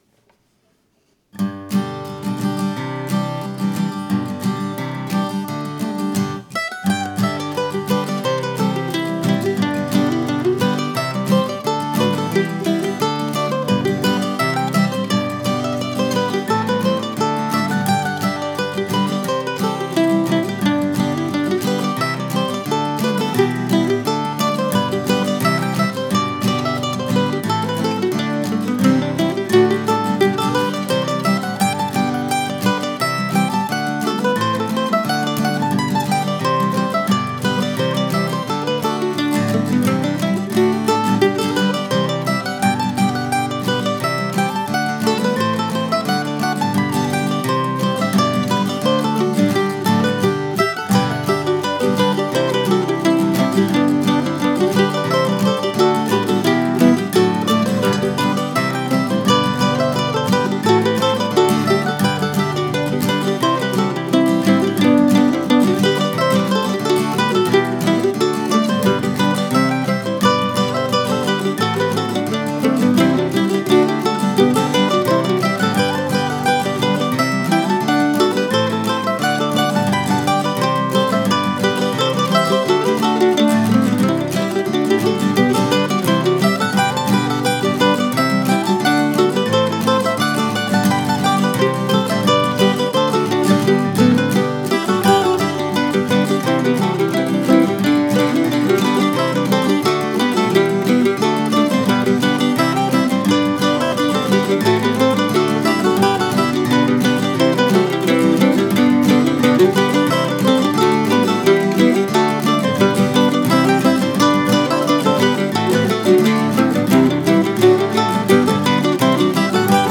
On a technical note, this is the first tune appearing in this blog that has been recorded using the Izotope Spire Studio device that I recently purchased.
In this instance I used a nice condenser mic of my own rather than the built in Spire mic and I did some final tweaking using Audacity but, overall, the Spire was very easy to work with.